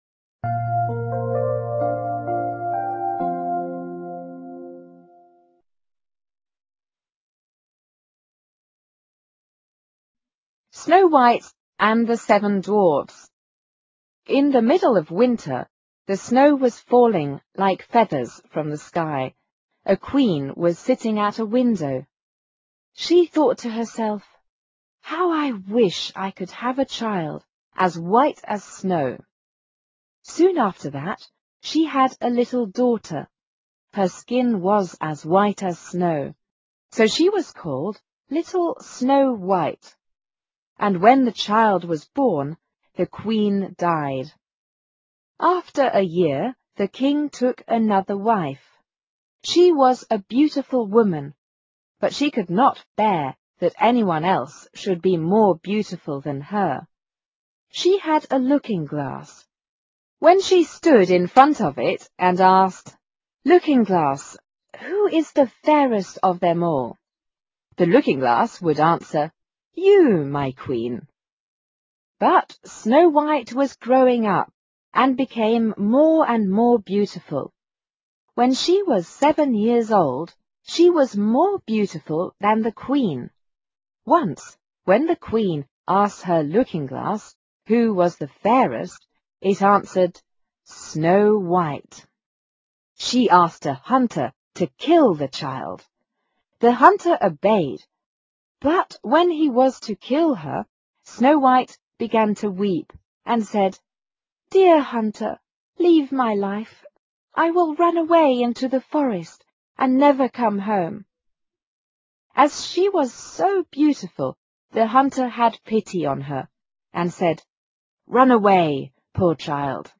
英文故事剧 白雪公主(Little Snow-white) 听力文件下载—在线英语听力室